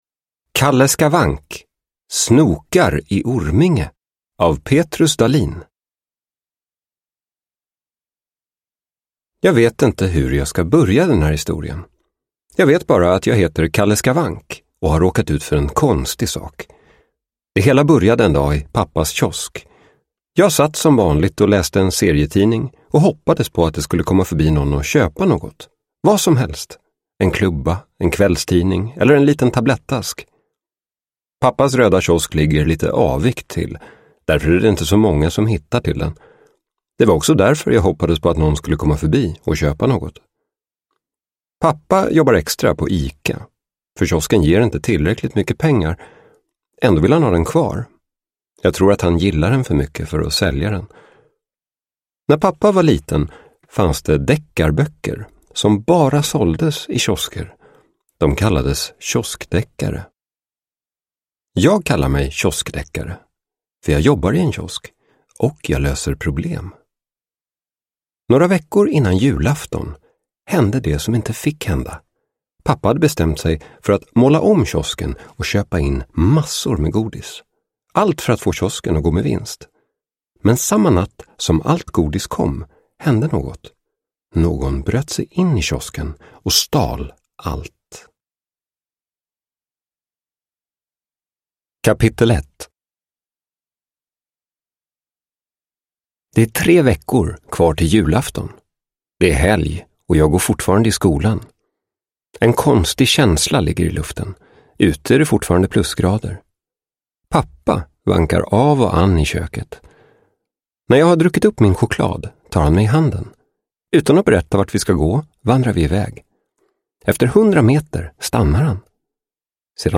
Snokar i Orminge – Ljudbok – Laddas ner